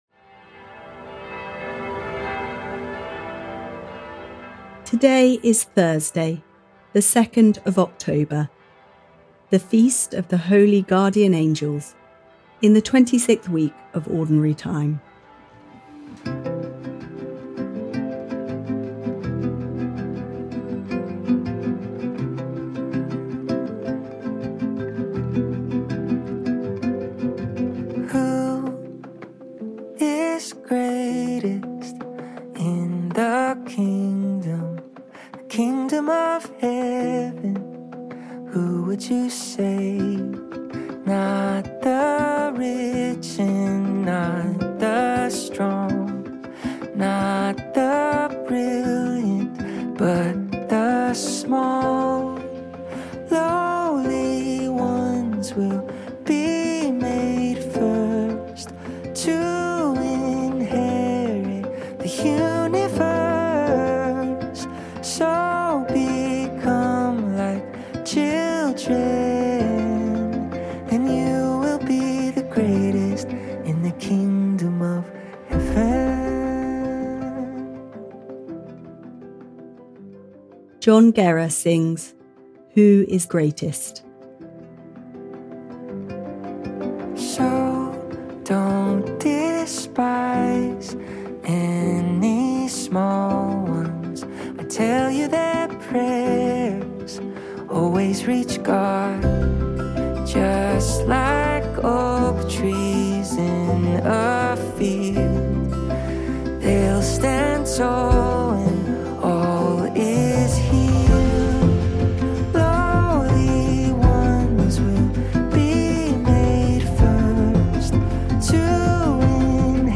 Pray As You Go - Daily Prayer Thursday 2 October 2025 - Who is the greatest?